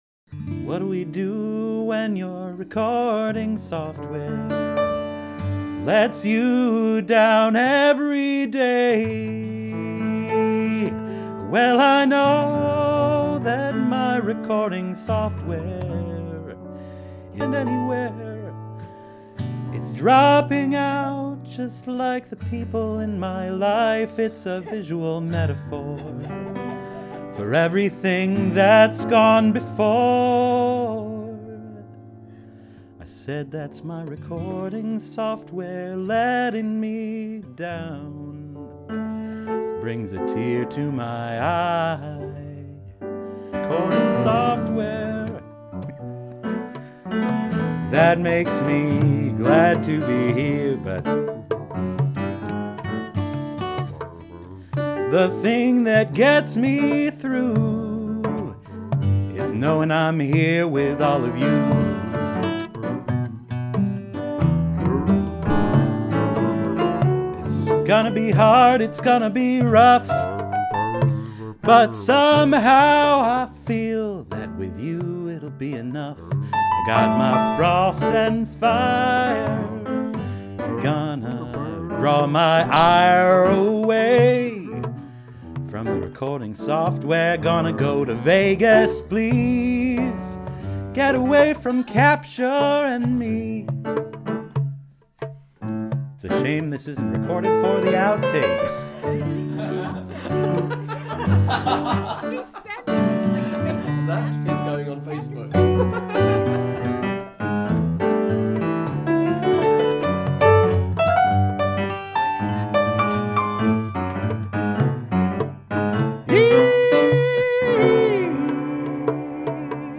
Broadway-show-tune-meets-blues-meets-Celtic lament